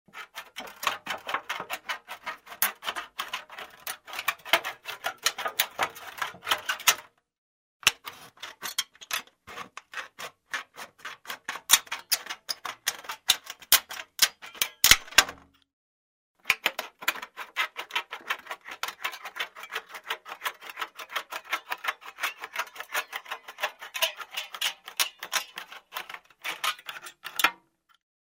Подборка включает четкие и реалистичные записи, которые подойдут для видео, подкастов или звукового оформления.
Звук резки металла ножницами